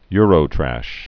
(yrō-trăsh)